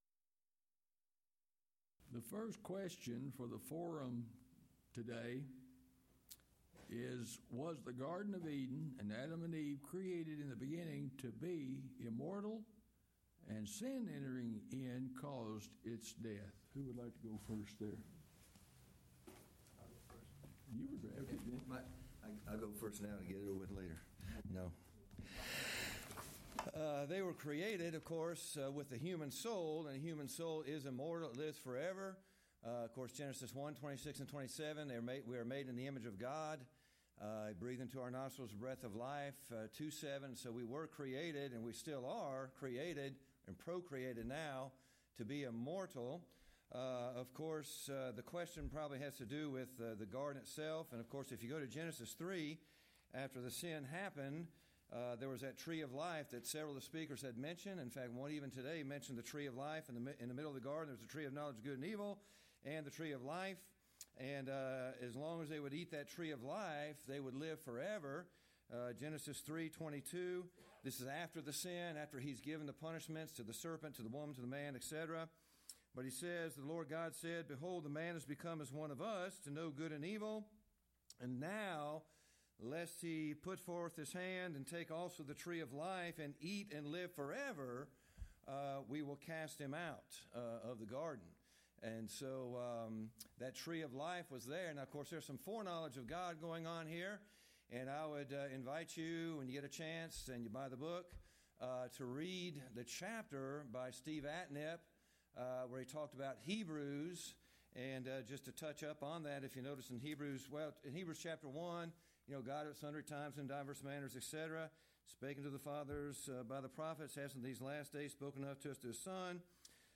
Title: Open Forum: Tuesday Speaker(s): Various Alternate File Link File Details: Series: Lubbock Lectures Event: 23rd Annual Lubbock Lectures Theme/Title: A New Heaven and a New Earth: Will Heaven Be On A "New Renovated" Earth?